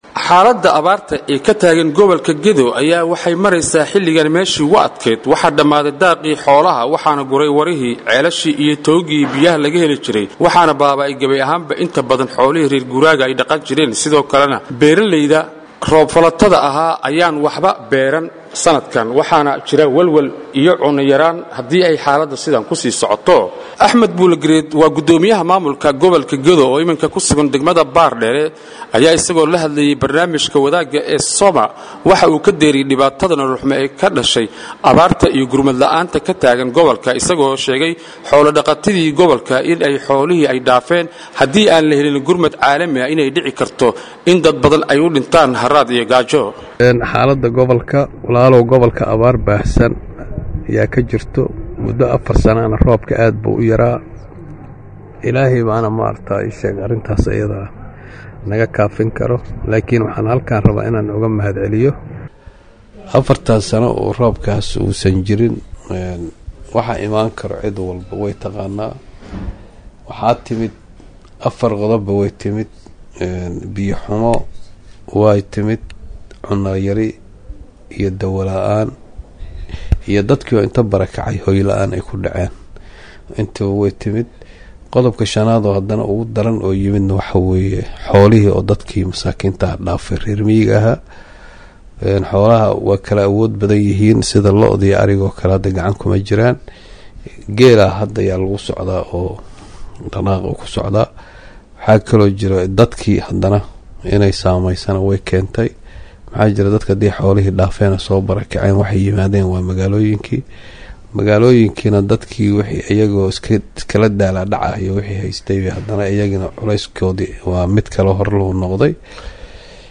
Axmed Buulle Gareed waa Guddoomiye Maamulka Gobolka Gedo oo iminka ku sugan degmada Baardheere ayaa isaga oo la hadlay Barnaamijka Wadaaga Radio Markabley ayaa waxa uu ka deyriyey dhibaatada nolol xumo ee ka dhashay abaarta iyo gurmad la’aanta ka taagan Gobolka, isagoo sheegay xoolo dhaqatii Gobolka in Xoolahii ay dhaafeen , haddii aan la helinna gurmad caalami ah in ay dhici karto in dad badan ay u dhintaan harraad iyo Gaajo.